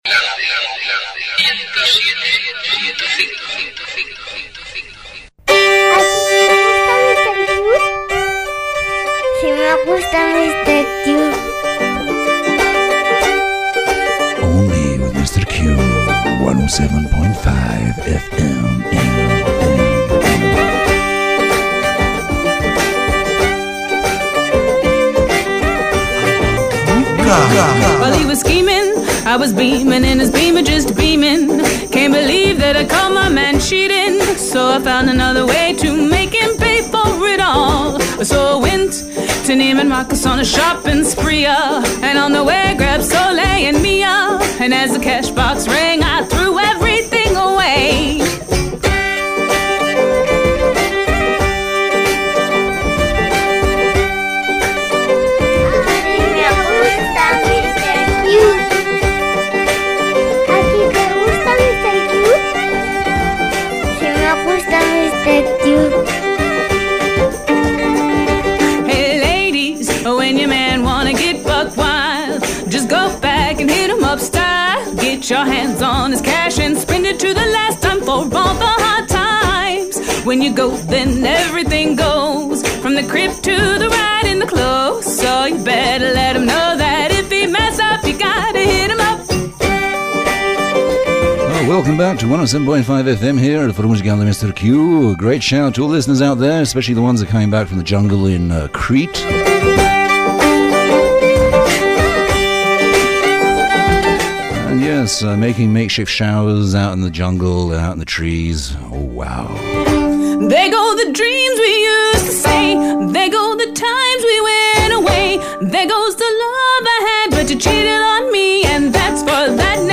Además el programa es bilingüe y podras escucharlo y entenderlo desde cualquier parte del mundo mundial, Today Double session...